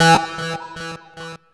Syncer Hi.wav